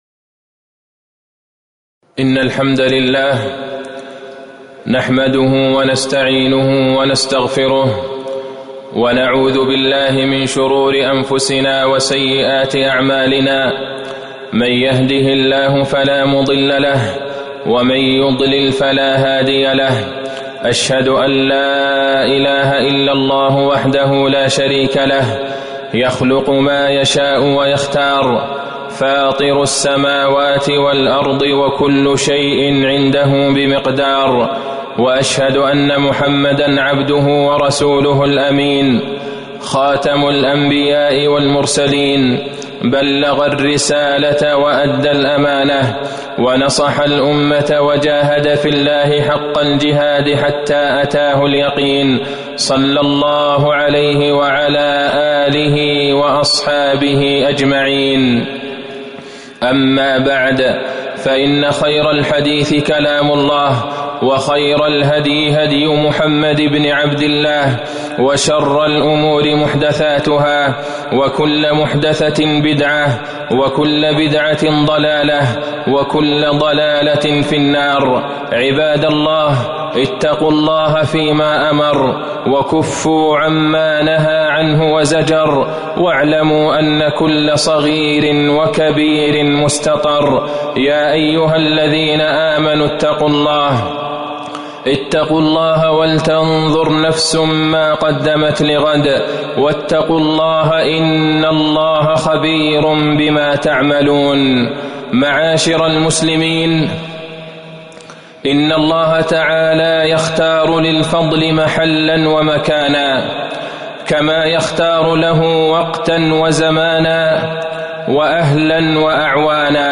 تاريخ النشر ٢٩ جمادى الأولى ١٤٤١ هـ المكان: المسجد النبوي الشيخ: فضيلة الشيخ د. عبدالله بن عبدالرحمن البعيجان فضيلة الشيخ د. عبدالله بن عبدالرحمن البعيجان فضل المدينة